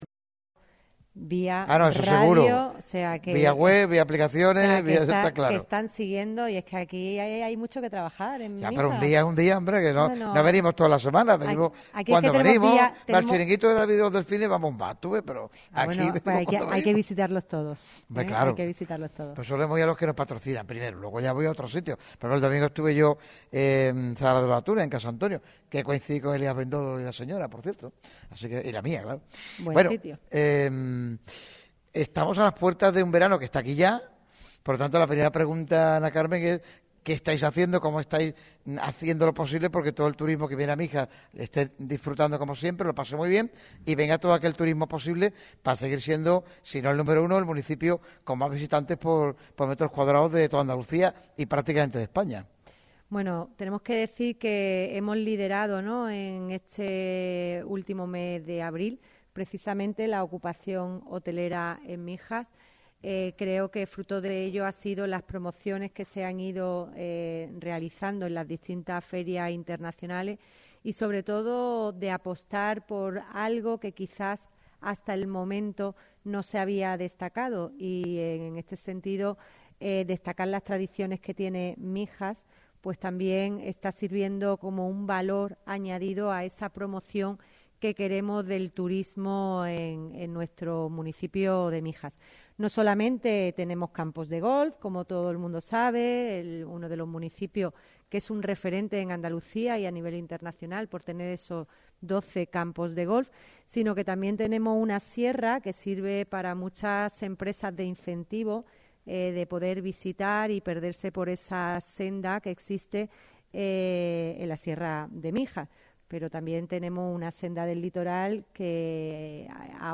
Ana Carmen Mata, alcaldesa de Mijas, se ha pasado por el programa especial de Radio MARCA Málaga dedicado al deporte mijeño que se ha celebrado en el salón de actos de la Tenencia de Alcaldía de la Cala de Mijas.